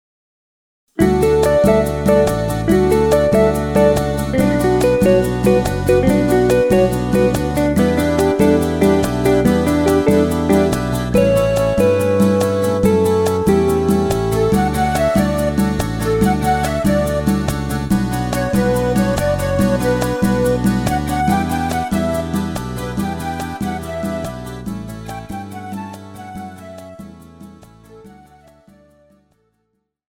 Žánr: Folk
BPM: 142
Key: D
MP3 ukázka